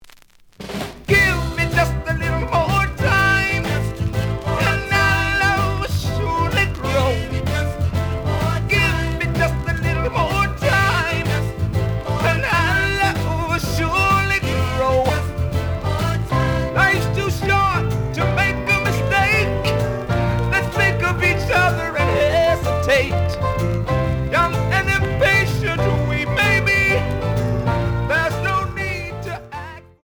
試聴は実際のレコードから録音しています。
●Genre: Soul, 70's Soul
●Record Grading: VG (盤に歪み。プレイOK。)